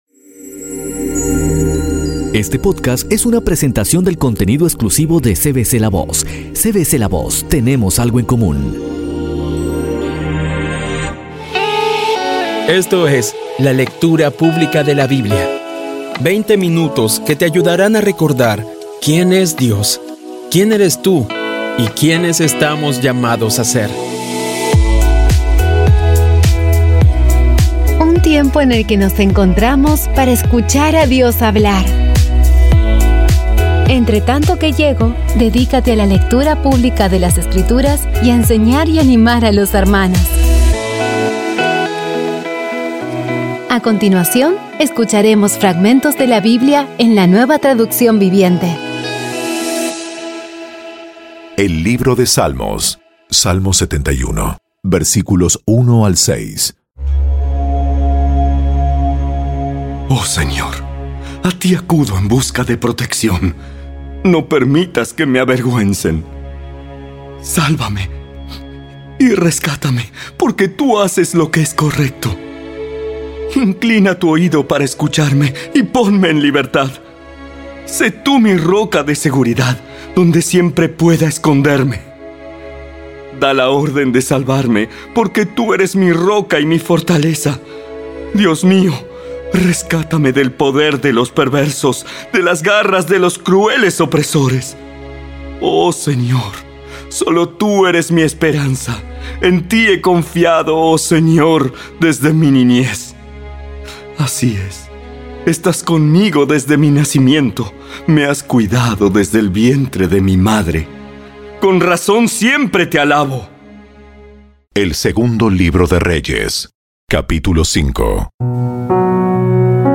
Audio Biblia Dramatizada Episodio 162
Poco a poco y con las maravillosas voces actuadas de los protagoni...